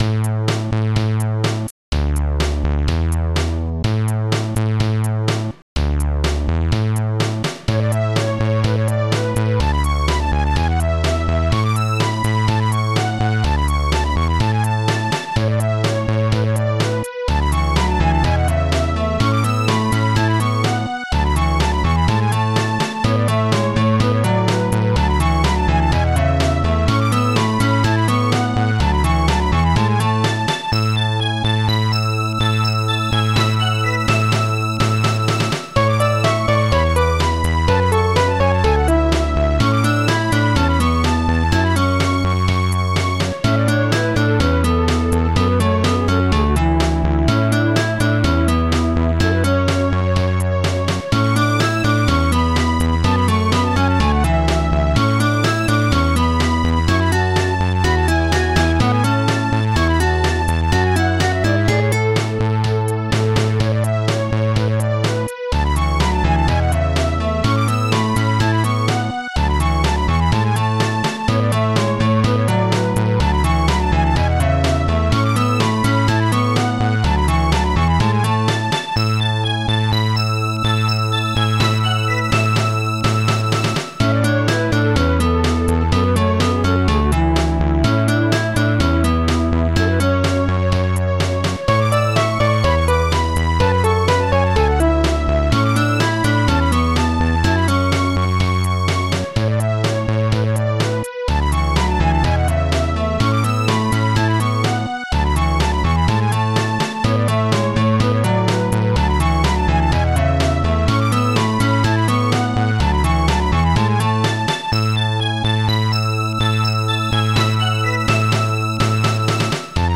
Protracker and family
PolySynth
RingPiano
HiHat2